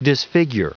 Prononciation du mot disfigure en anglais (fichier audio)
Prononciation du mot : disfigure